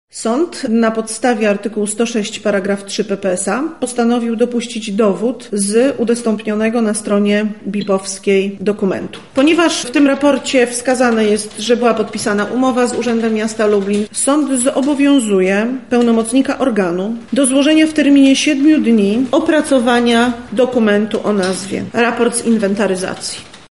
• mówi sędzia Marta Laskowska-Pietrzak, prezes Wojewódzkiego Sądu Administracyjnego w Lublinie.